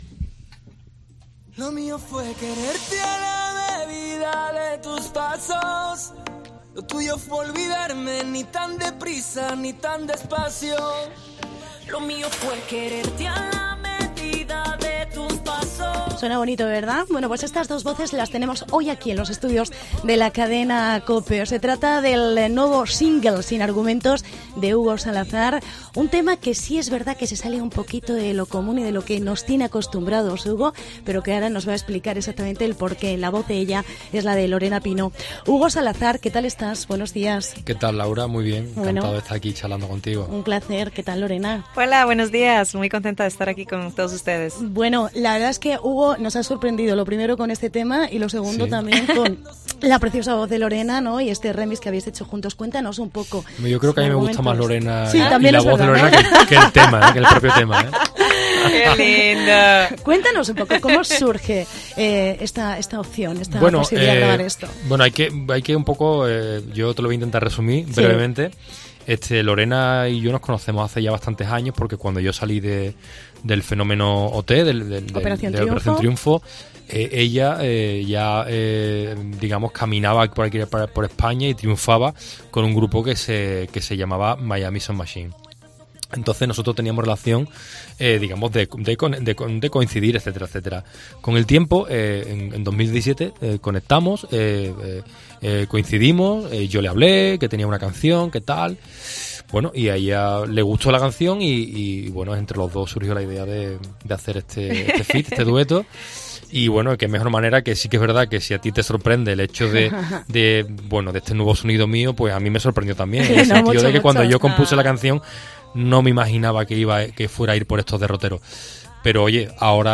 Entrevista en COPE Toledo